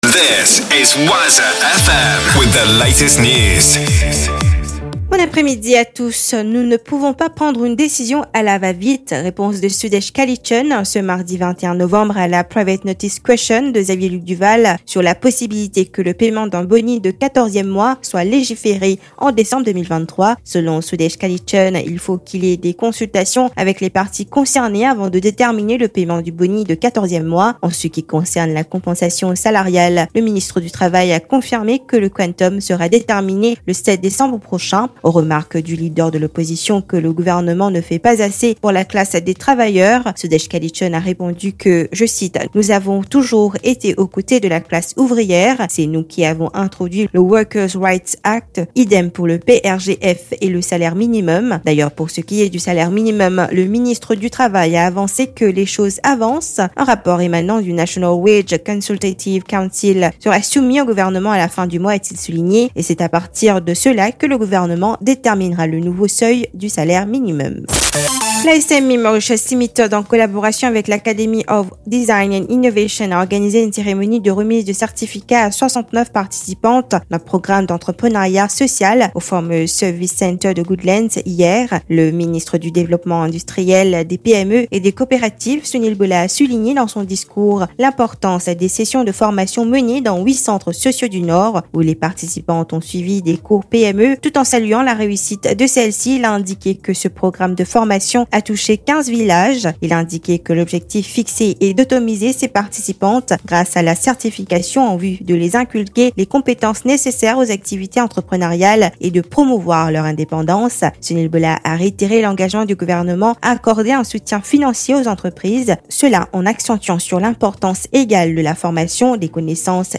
NEWS 15H - 21.12.23